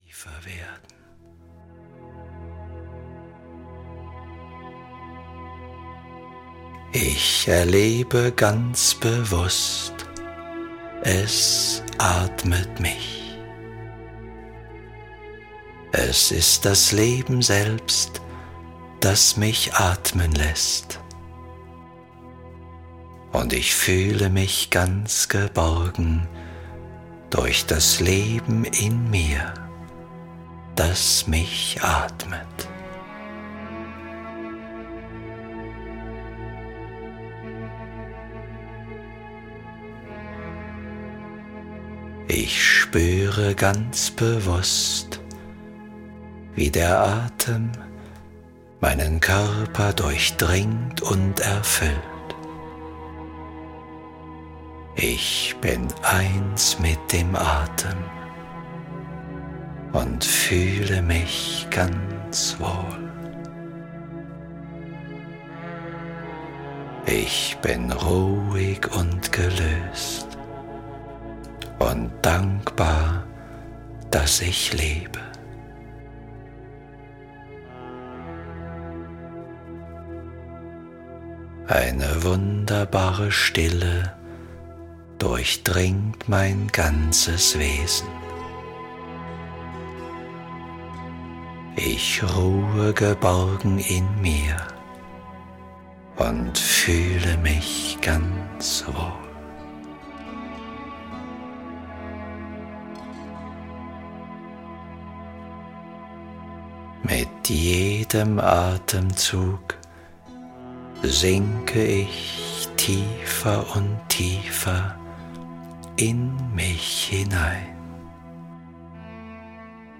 Mental Flow: Kreativität - Kurt Tepperwein - Hörbuch